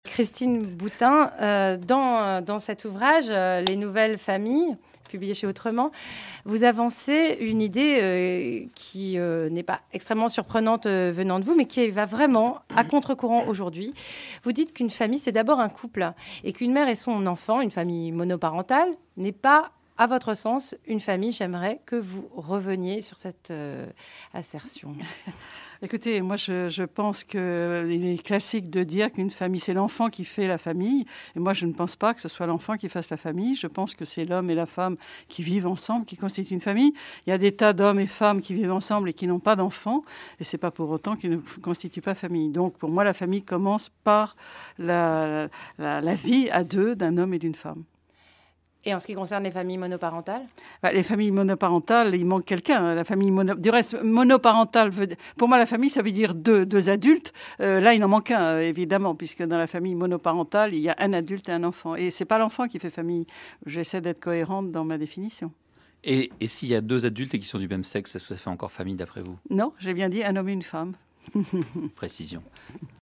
Extrait du Grain à Moudre du 19/05/2011 – France Culture